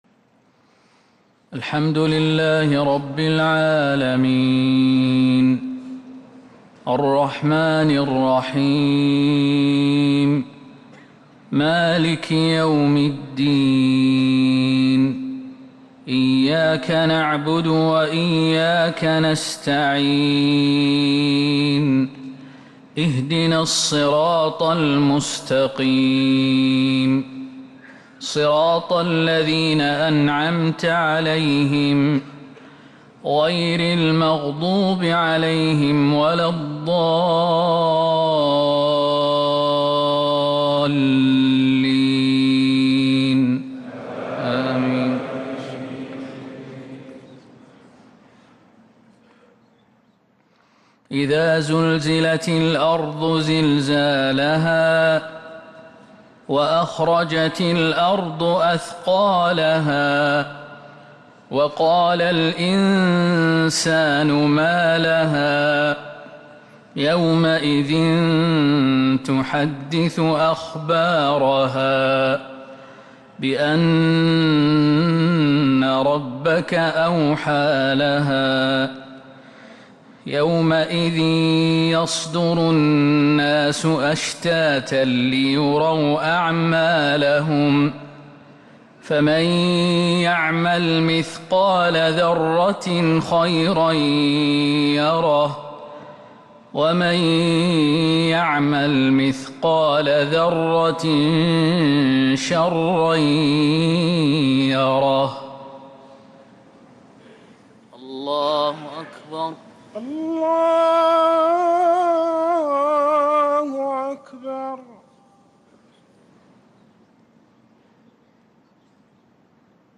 Haramain Salaah Recordings: Madeenah Maghrib - 05th April 2026
Madeenah Maghrib - 05th April 2026